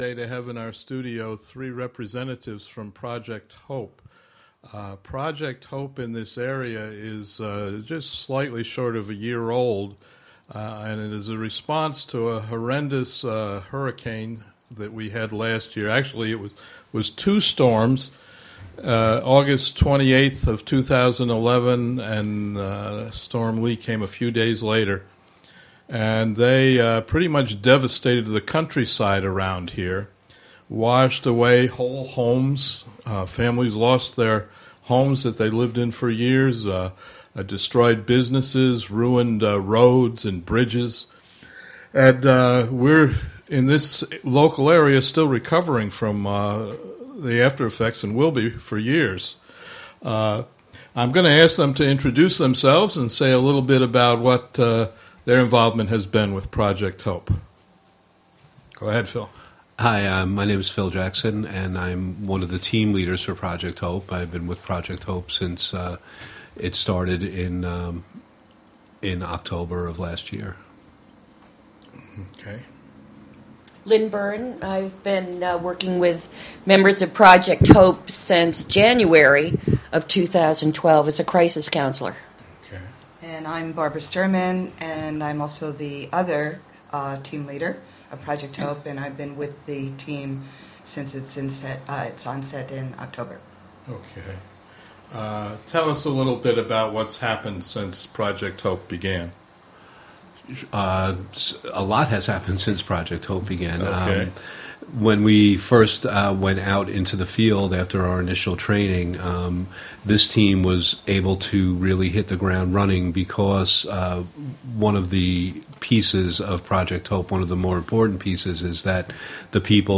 From "WGXC Afternoon Show" at WGXC's Wave Farm studio in Acra.